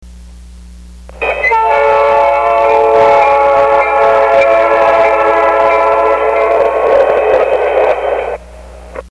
Each of these recordings was made with an inexpensive microphone placed directly over the speaker of the sound module. To my ear they are all very similar and of good quality.
Playback of the original wave file recorded by the stock module's microphone and played back through its small speaker
train_crossing_stock_bone_mic_speaker.mp3